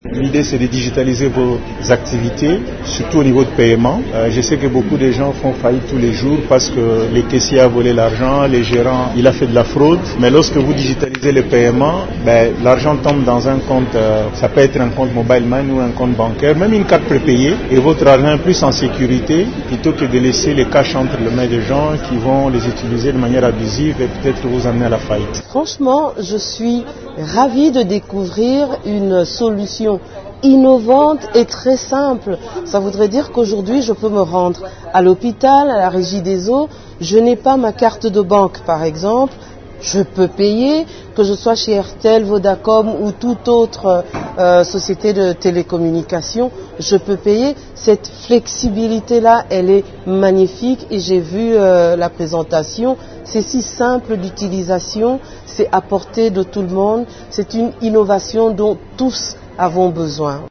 Le système de paiement digital FlexPaie a été officiellement lancé ce jeudi 30 octobre au Fleuve Congo Hôtel en présence de partenaires du secteur financier, d’acteurs technologiques et de personnalités institutionnelles.